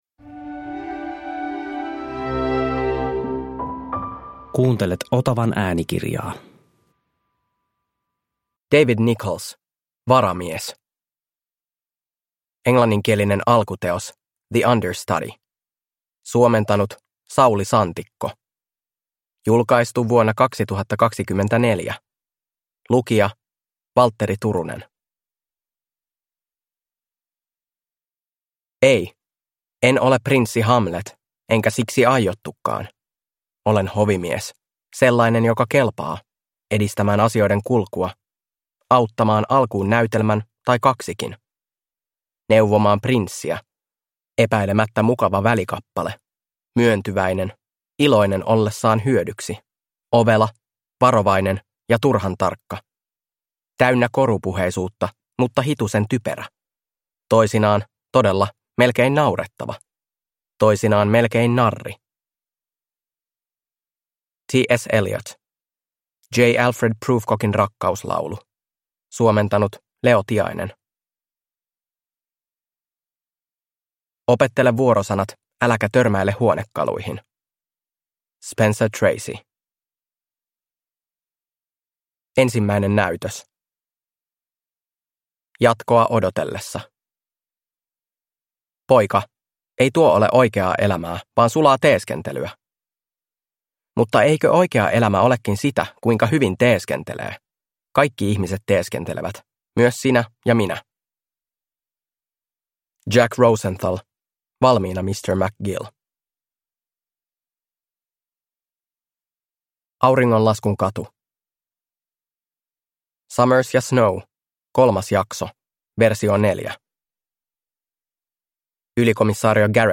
Varamies – Ljudbok